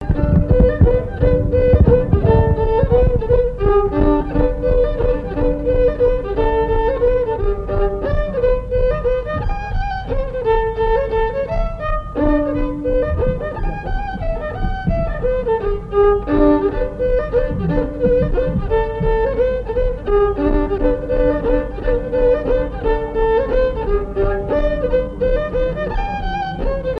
Enfantines - rondes et jeux
danse : mazurka
Assises du Folklore
Pièce musicale inédite